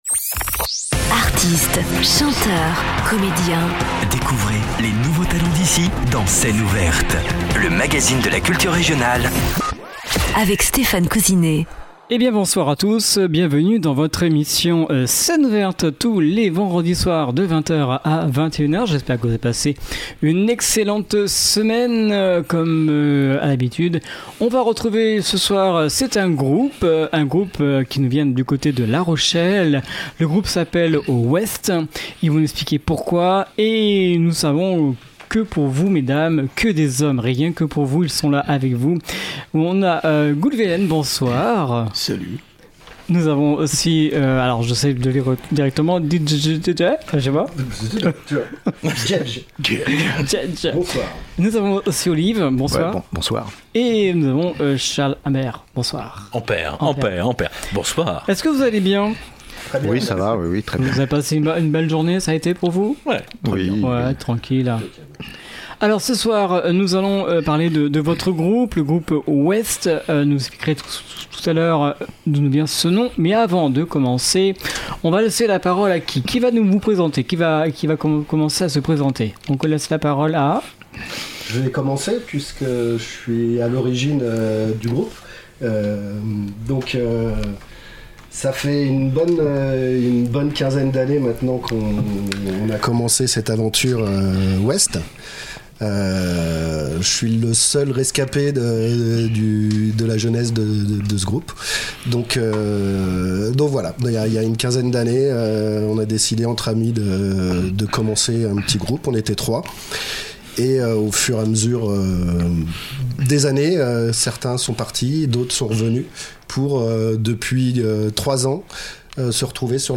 Puissant et énergique
quatuor rochelais